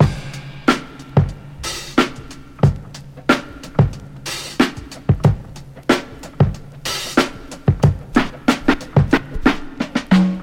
92 Bpm Breakbeat Sample F# Key.wav
Free breakbeat - kick tuned to the F# note. Loudest frequency: 939Hz
92-bpm-breakbeat-sample-f-sharp-key-4Qb.ogg